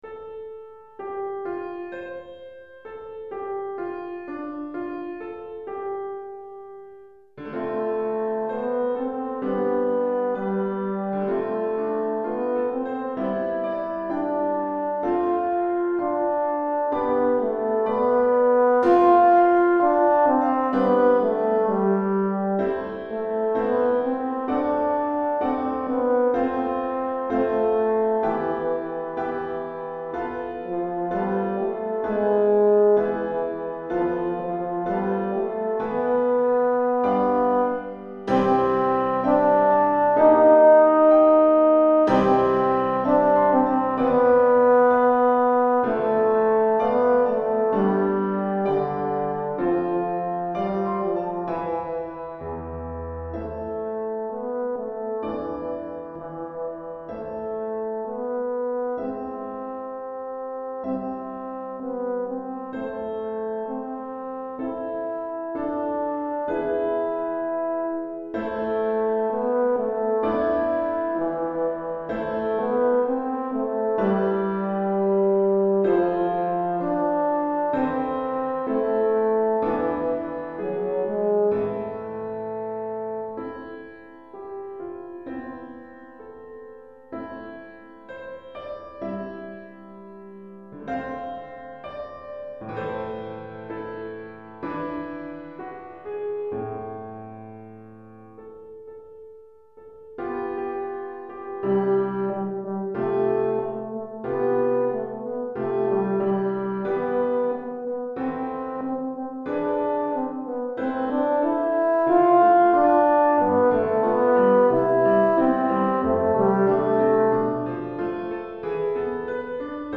pour cor et piano